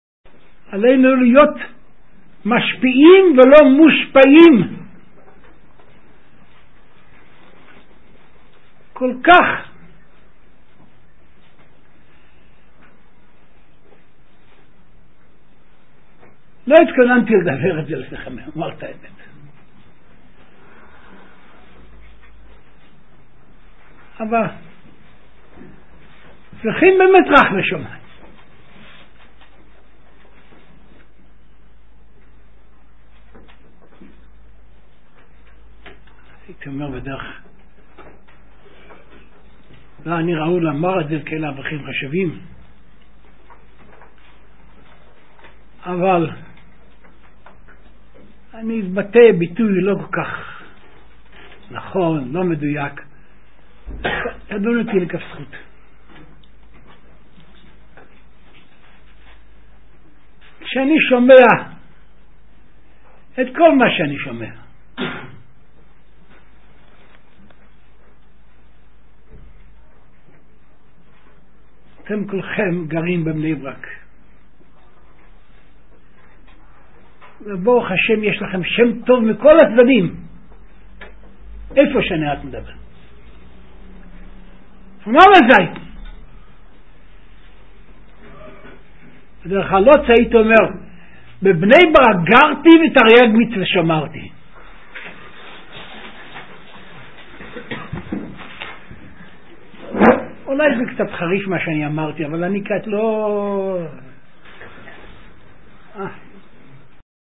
גרודנא ב''ב יום ב' ל' כסלו תשע''ב: